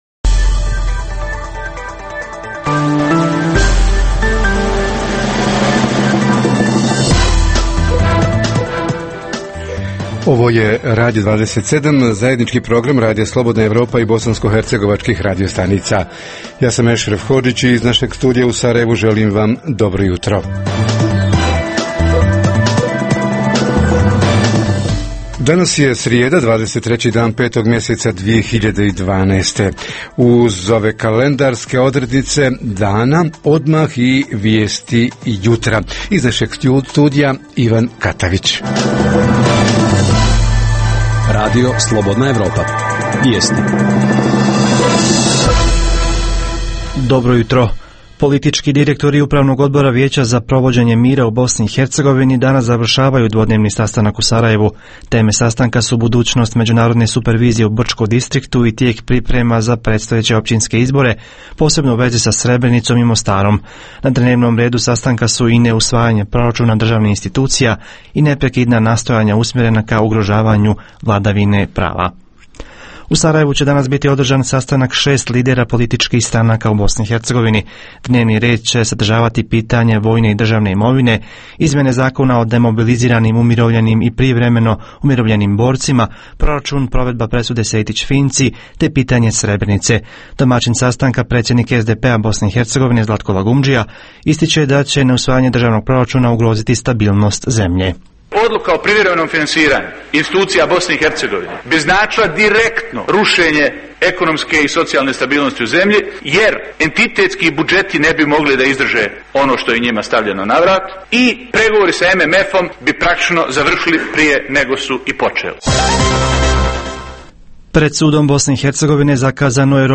Svršeni osnovci i srednjoškolci-maturanti – gdje ove godine na đačku ekskurziju – kakva je reakcija na inicijativu da se prednost dadne domaćim, bh. destinacijama? Reporteri iz cijele BiH javljaju o najaktuelnijim događajima u njihovim sredinama.